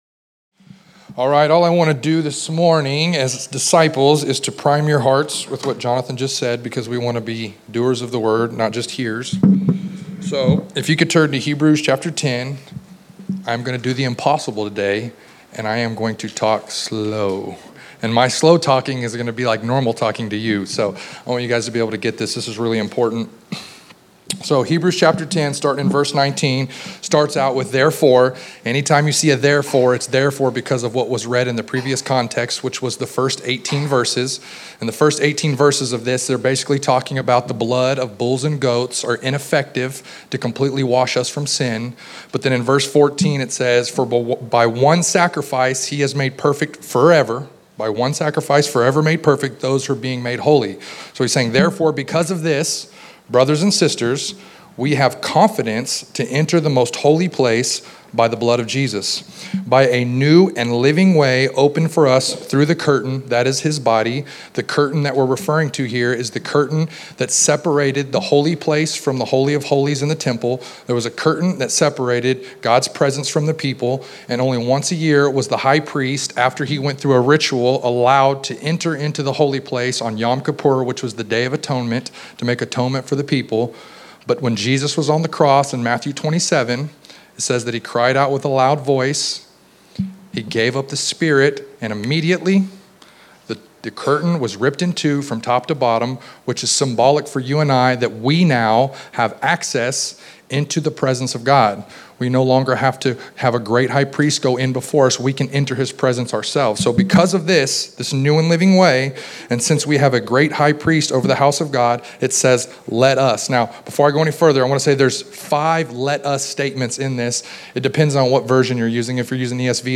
Sermons :